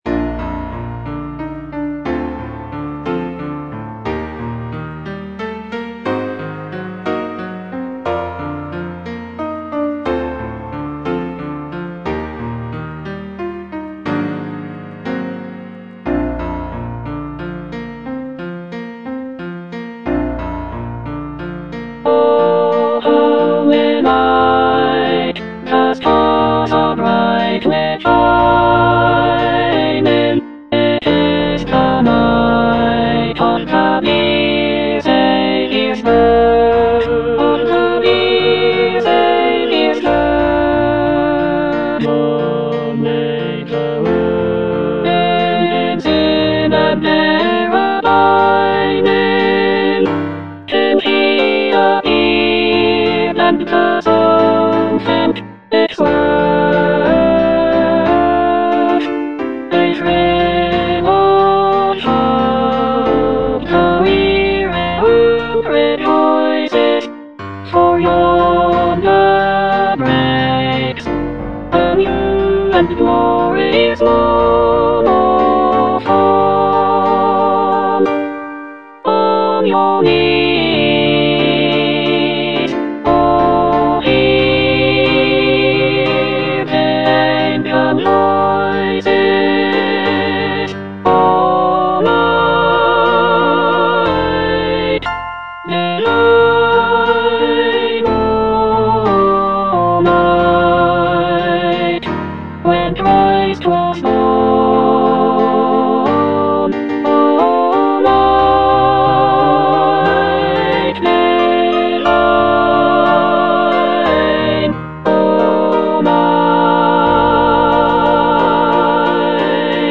Alto (Emphasised voice and other voices)